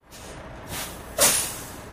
tr_dieseltruck_brake_02_hpx
Diesel truck air brakes release and screech. Vehicles, Truck Brake, Release